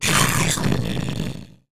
sounds / monsters / fracture / die_0.ogg
die_0.ogg